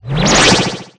Wind3.ogg